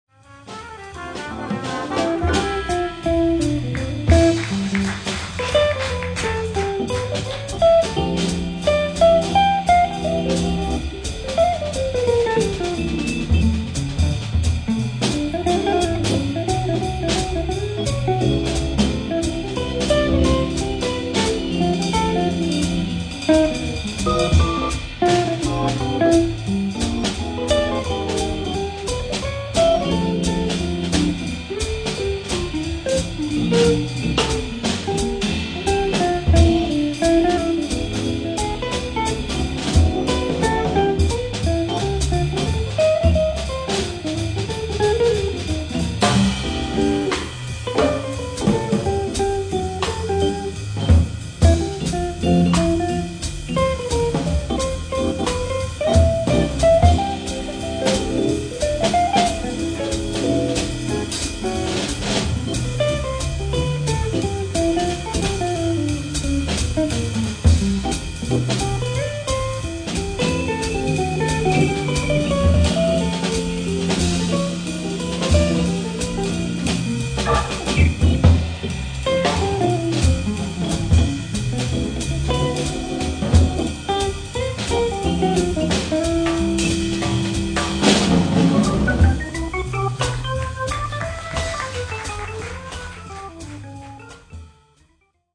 type: Selmer
Warm, dynamisch, zacht.